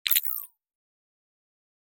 جلوه های صوتی
دانلود صدای ربات 72 از ساعد نیوز با لینک مستقیم و کیفیت بالا